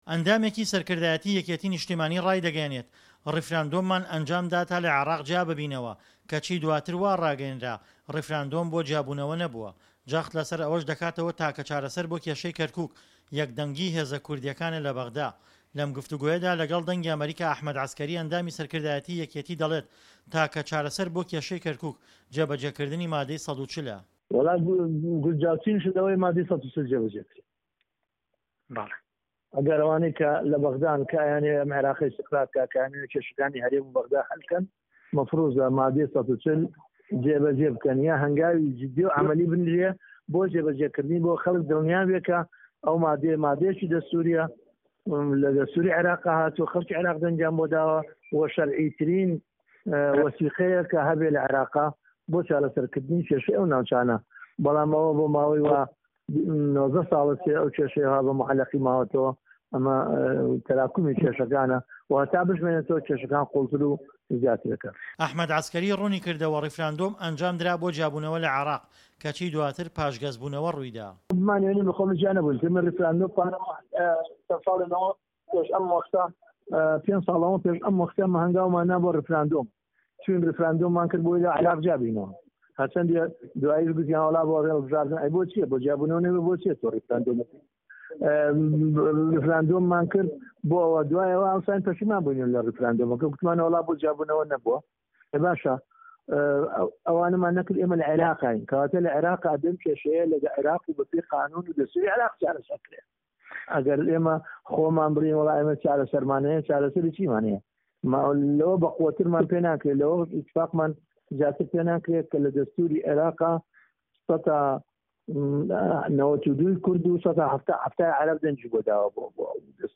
ڕاپۆرتی پەیامنێر
وتوێژ